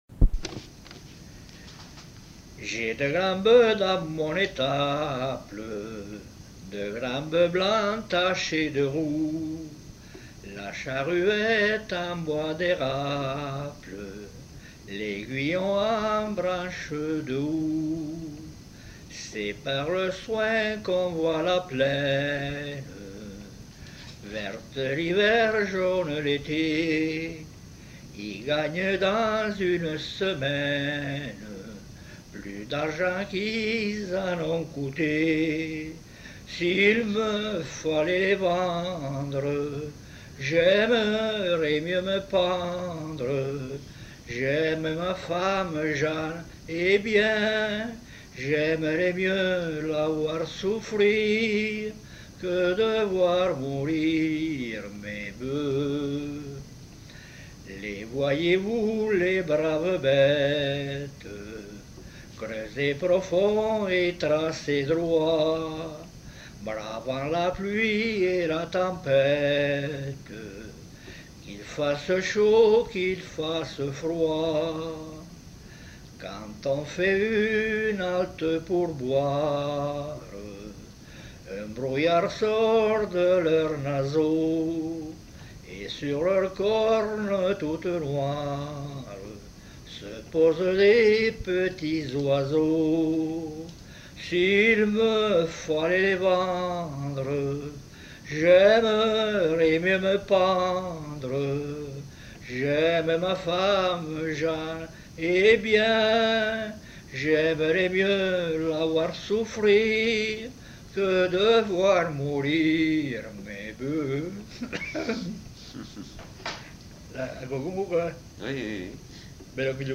Genre : chant
Effectif : 1
Type de voix : voix d'homme
Production du son : chanté
Description de l'item : fragment ; 2 c. ; refr.